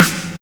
SNARE126.wav